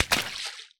water_splash_small_item_01.wav